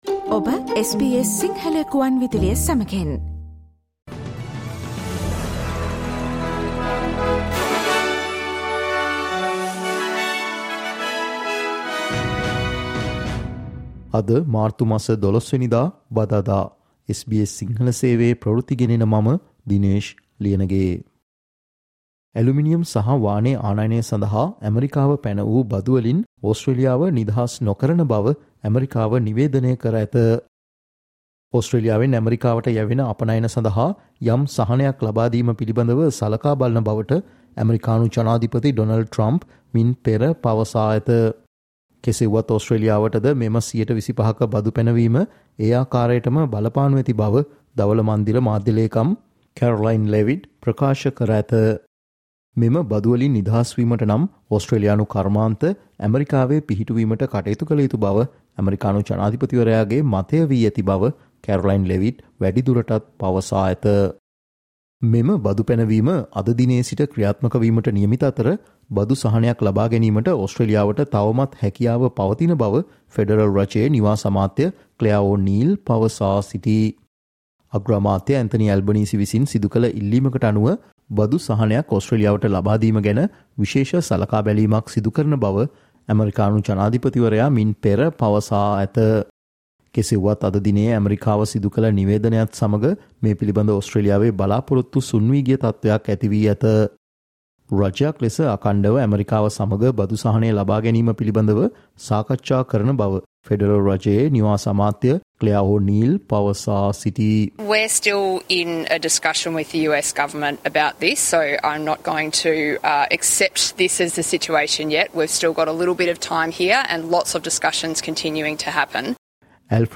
Listen to the SBS Sinhala Newsflash on 12 March 2025